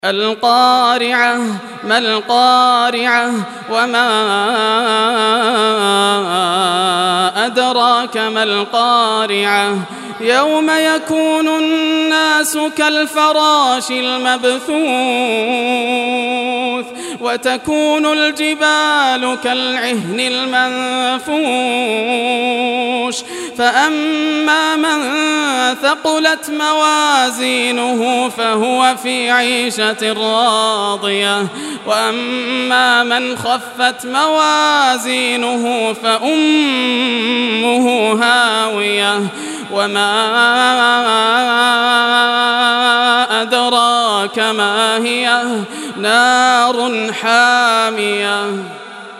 Surah Qariah Recitation by Yasser al Dosari
101-surah-qariah.mp3